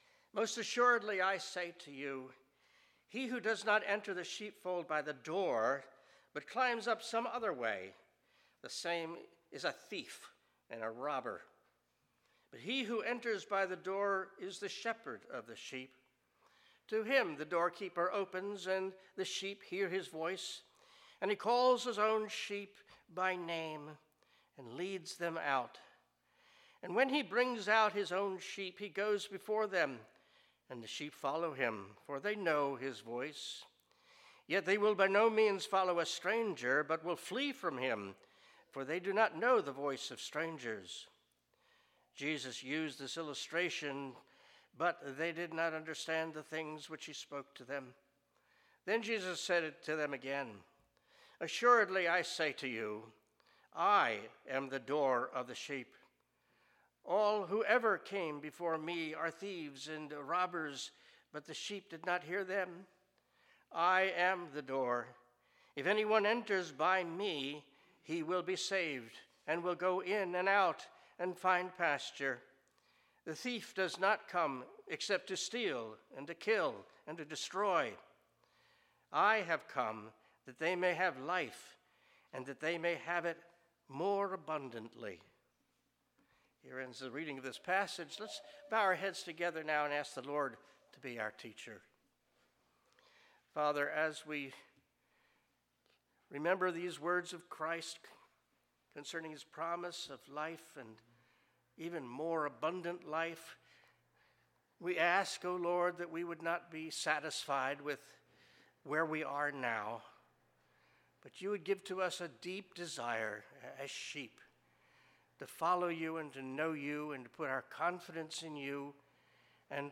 Passage: John 10:1-10 Service Type: Worship Service « Fathers Direct